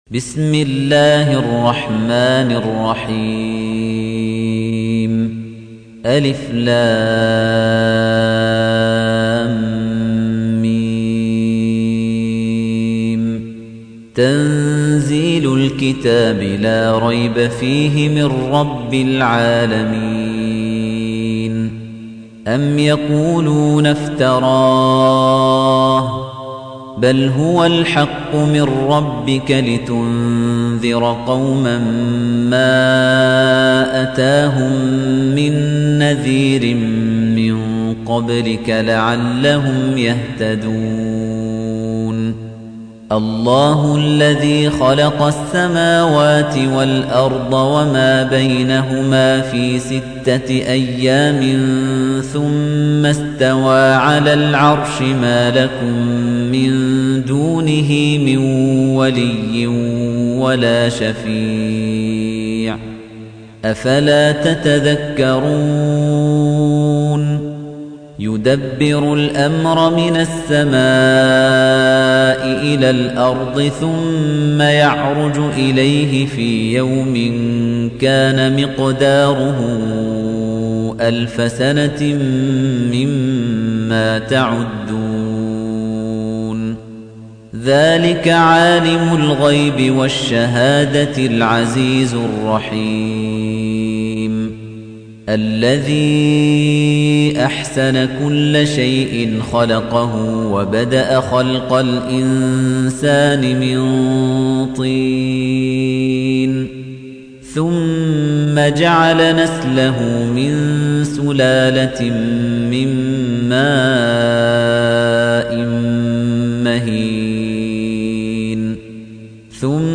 تحميل : 32. سورة السجدة / القارئ خليفة الطنيجي / القرآن الكريم / موقع يا حسين